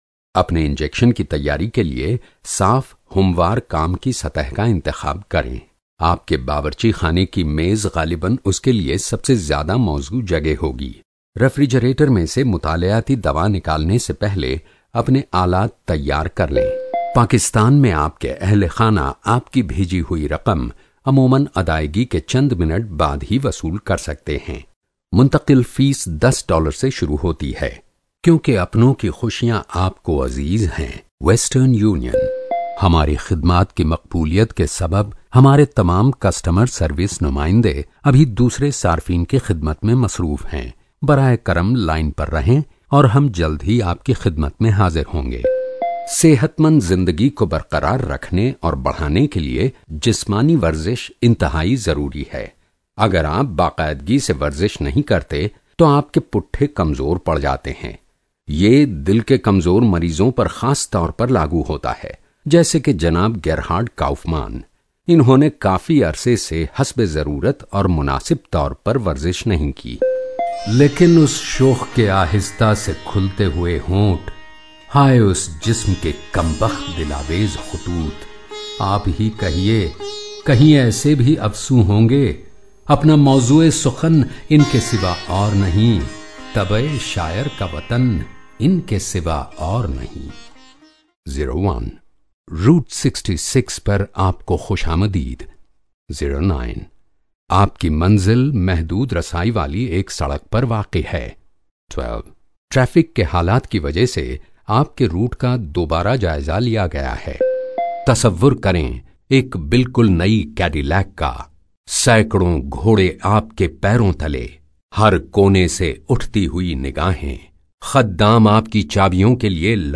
Male Indian voice over artist
Sprechprobe: eLearning (Muttersprache):